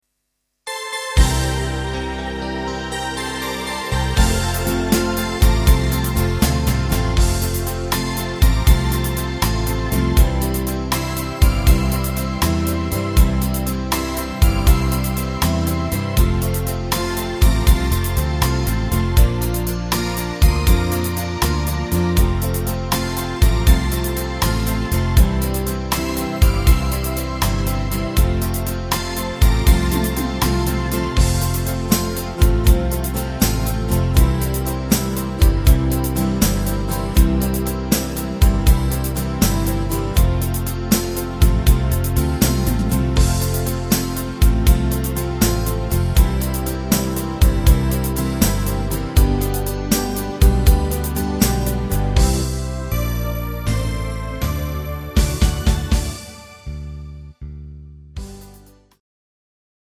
Slowrock Sax-Medley